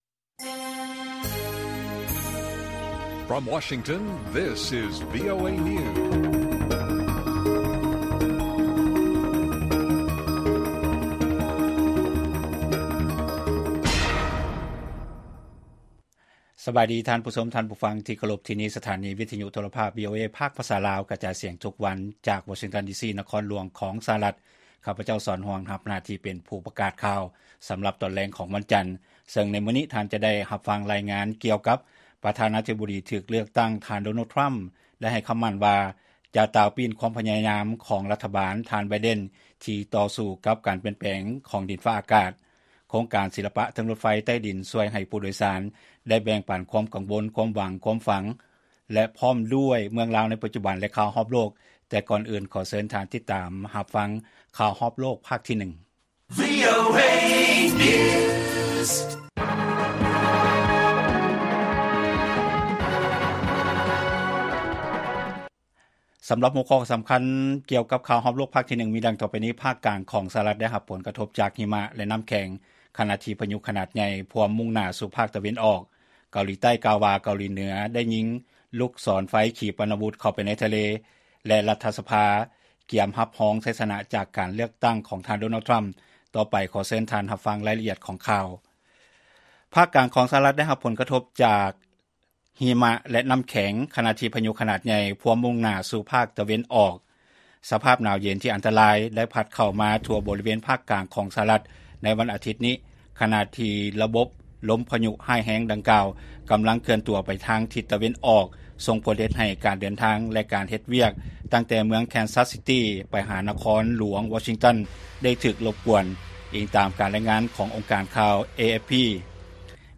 ລາຍການກະຈາຍສຽງຂອງວີໂອເອລາວ: ພາກກາງຂອງ ສະຫະລັດ ໄດ້ຮັບຜົນກະທົບຈາກຫິມະ ແລະນໍ້າແຂງ ຂະນະທີ່ພາຍຸຂະໜາດໃຫຍ່ພວມມຸ່ງໜ້າສູ່ພາກຕາເວັນອອກ